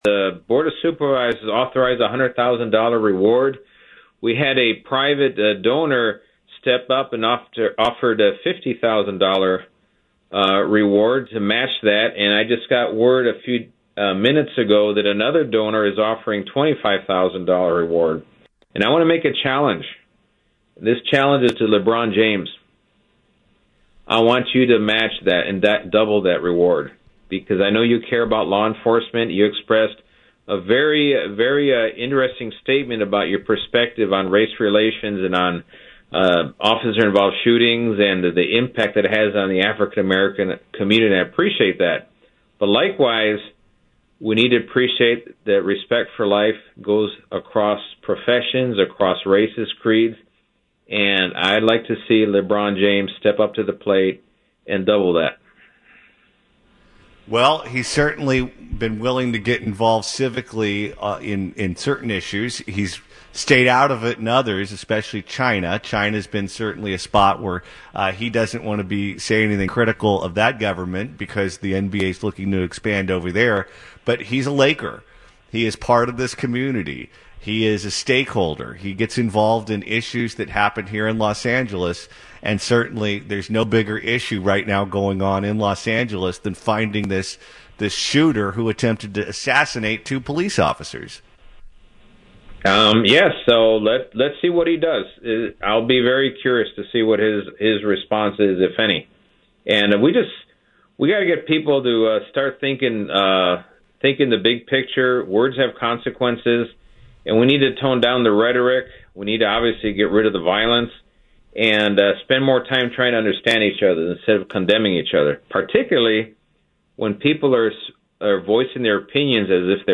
Los Angeles County Sheriff Alex Villanueva, speaking exclusively on 790 KABC Radio about the ambush of two LA County Sheriff’s deputies, issued a challenge to LA Lakers star and social activist LeBron James.